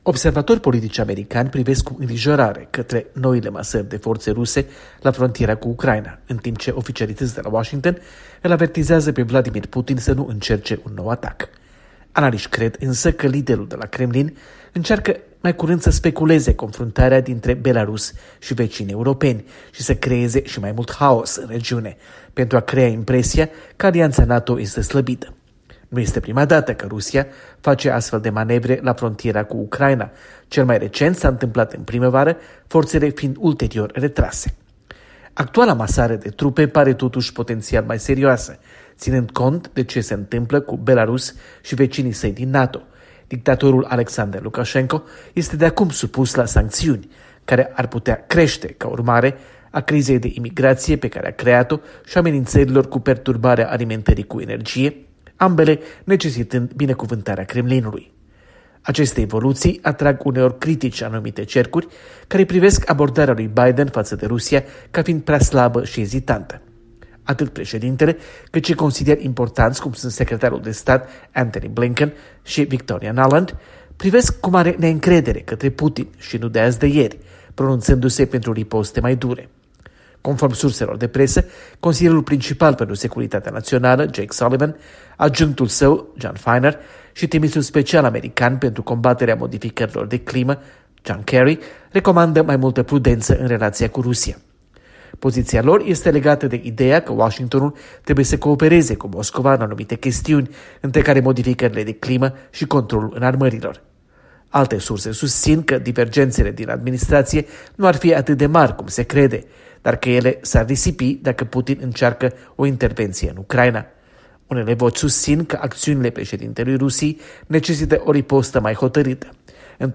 Corespondenta de la Washington